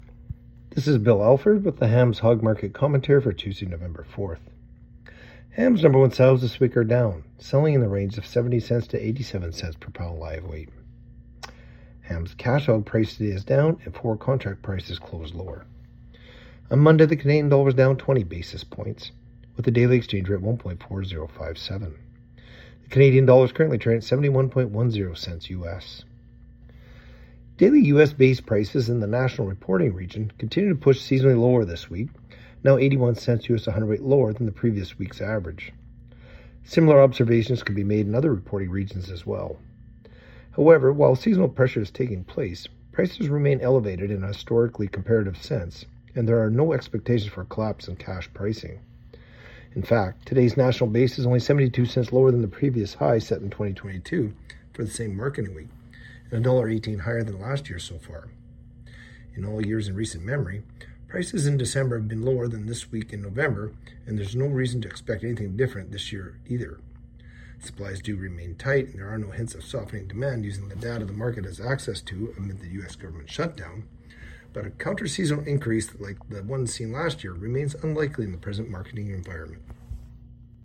Hog-Market-Commentary-Nov.-4-25.mp3